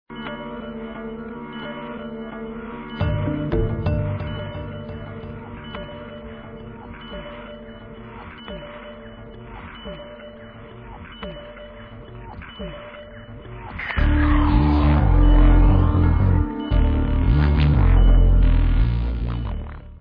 sledovat novinky v oddělení Dance/Drum & Bass